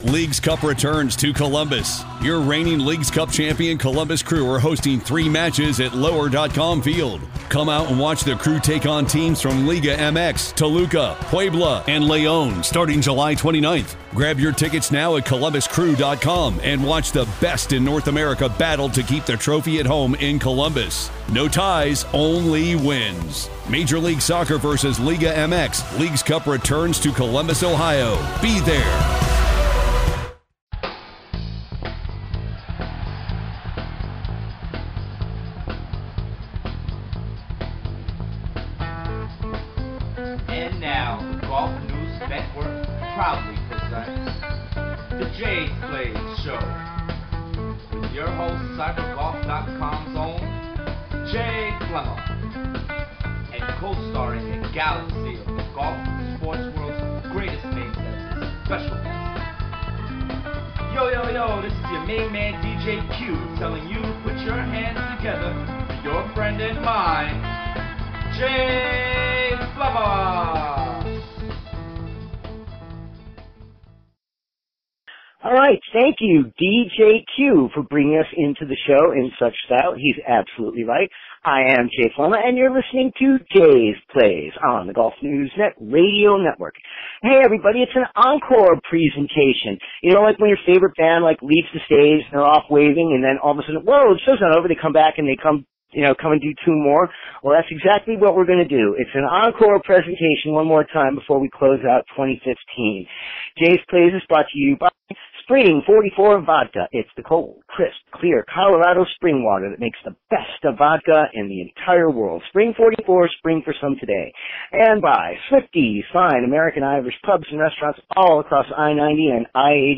wide-ranging conversation ahead of her big New Year's 2016 show.